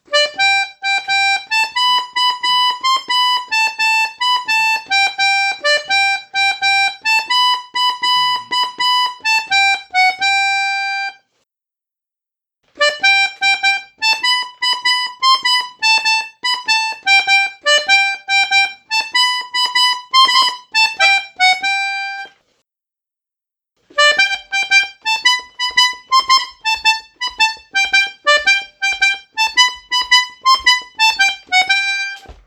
Dreimal hintereinander dasselbe Thema.
Anhänge Folk.mp3 Folk.mp3 1,2 MB · Aufrufe: 1.568